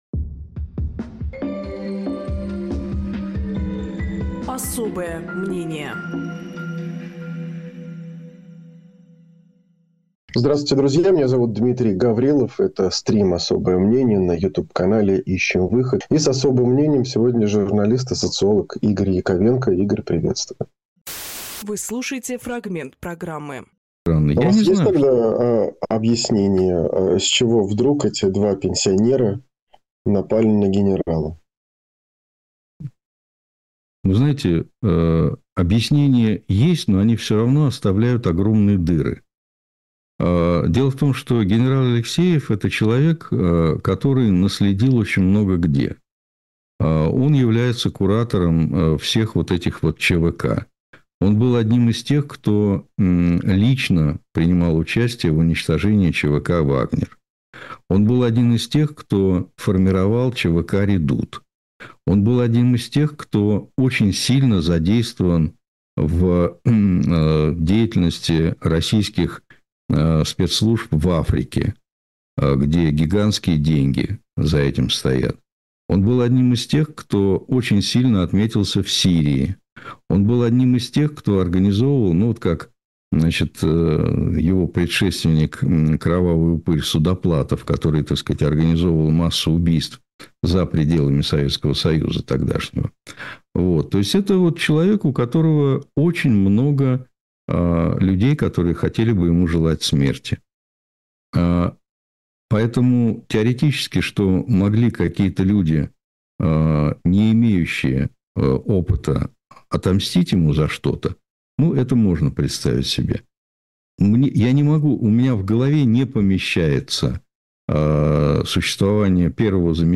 Фрагмент эфира от 12.02.2026